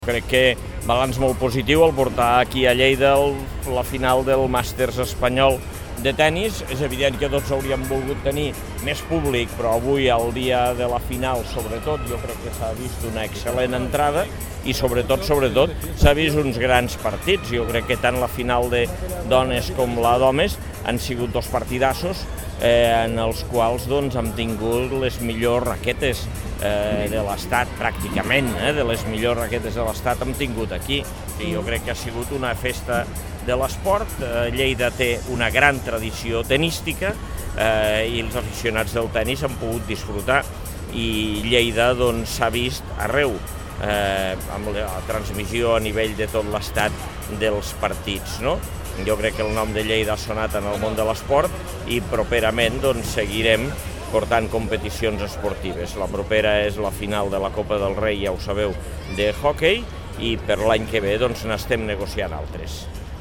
tall-de-veu-de-lalcalde-angel-ros-sobre-el-master-nacional-mapfre-de-tennis-que-sha-jugat-a-lleida